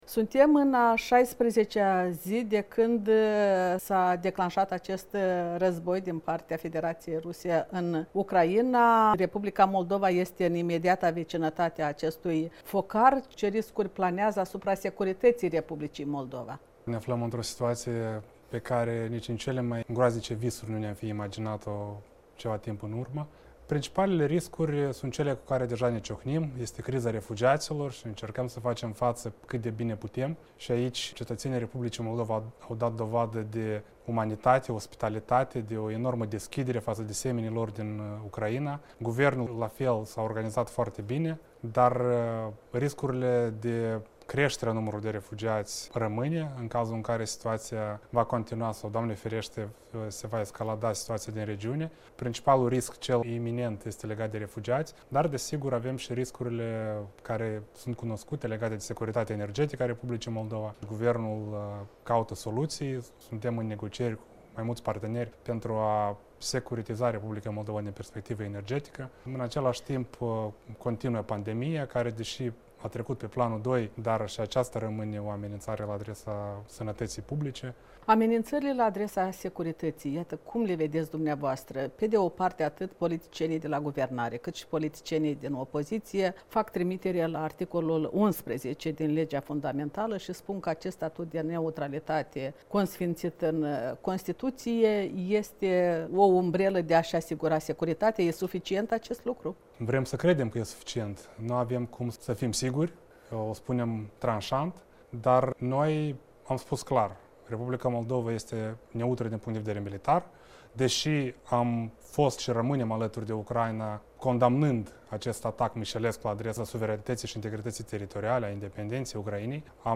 în dialog cu Mihai Poșoi